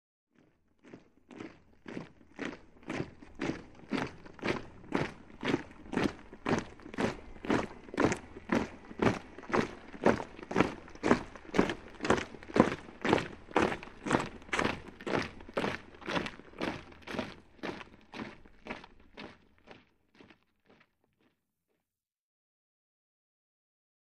Troops March | Sneak On The Lot
Troop Marching; Platoon Synchronized Marching At Medium Distance. Then Marches Away To Distant. Footsteps.